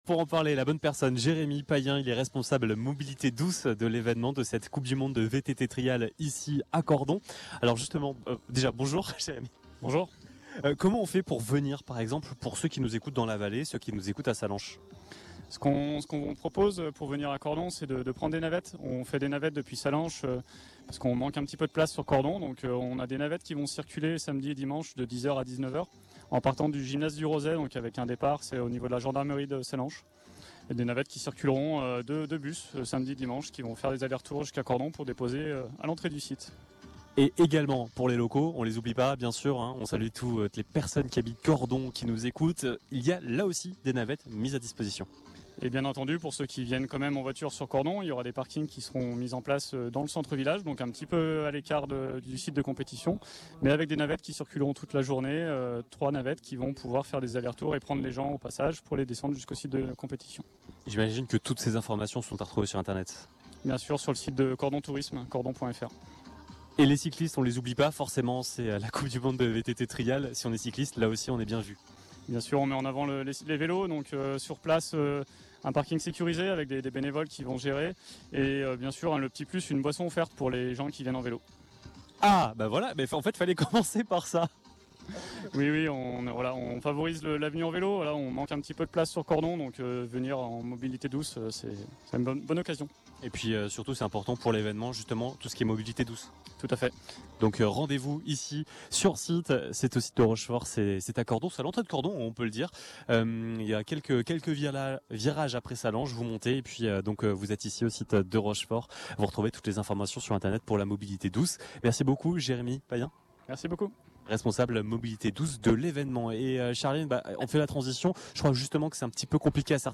À l’occasion de la Coupe du Monde de VTT Trial, nous étions en direct de Cordon pour une émission spéciale en direct du cœur de l’événement.
Interview